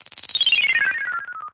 dicespin.mp3